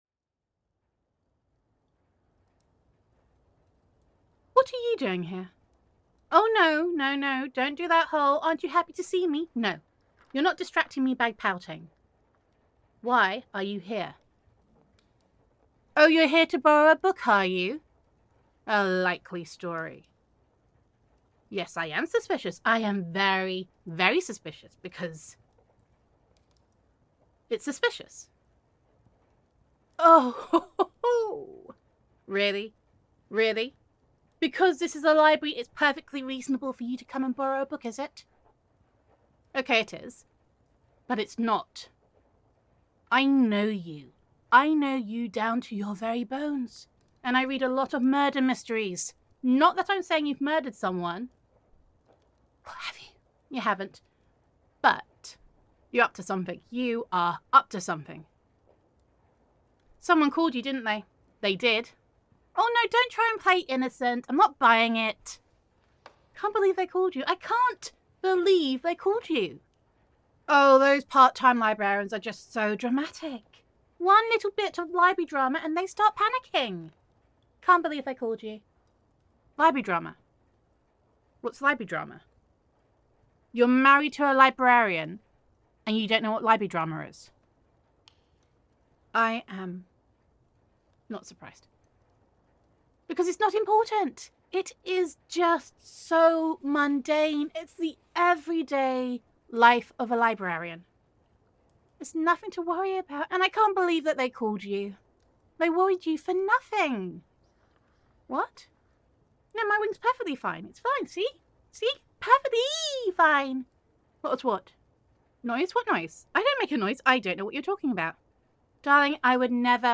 [F4A]
[Owl Girl Roleplay]